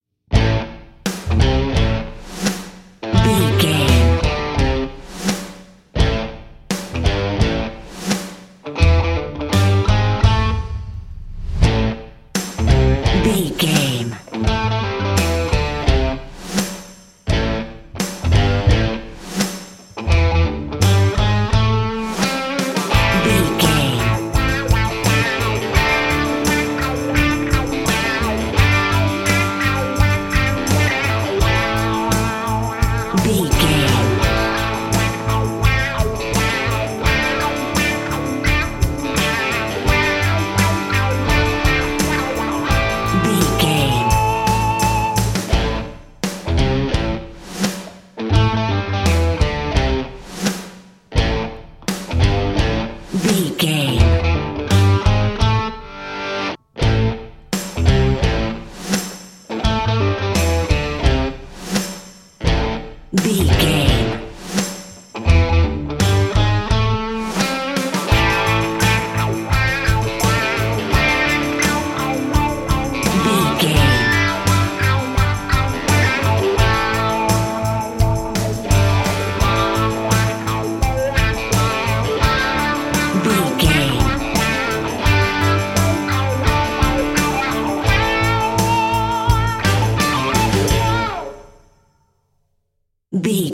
Aeolian/Minor
bass guitar
electric guitar
drum machine
percussion
aggressive
driving
energetic
heavy